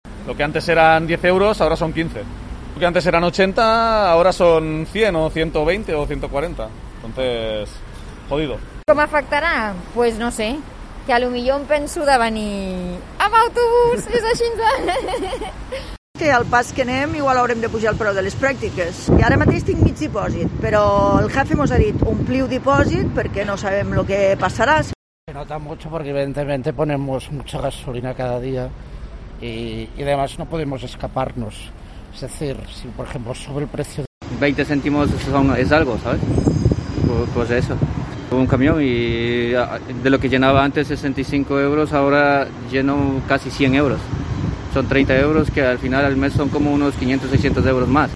Usuarios en las gasolineras explican como les afecta el aumento de precios